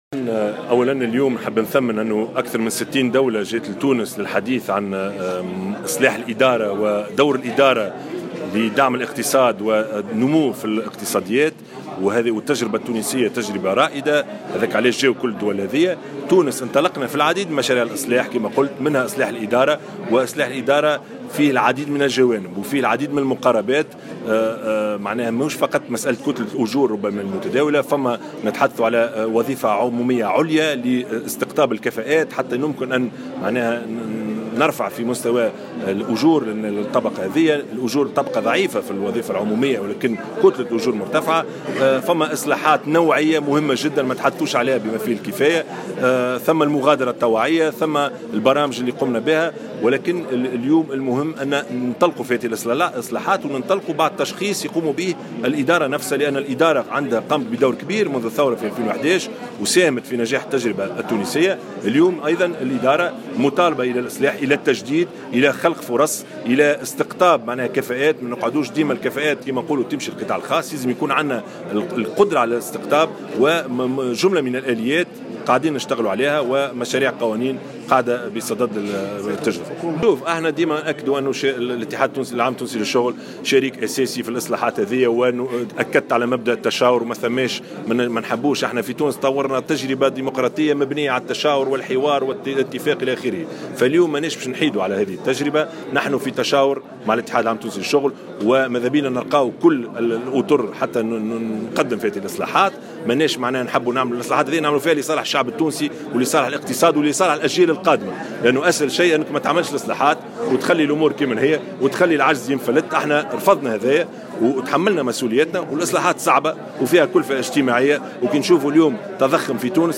وشدّد رئيس الحكومة في تصريح لـ "الجوهرة اف أم" على هامش افتتاحه أشغال المؤتمر السنوي للمعهد الدولي للعلوم الادارية، على أهمية الاصلاحات بالوظيفة العمومية والقدرة على استقطاب الكفاءات مستقبلا، مشيرا إلى مشاريع قوانين بصدد حكومته النظر فيها.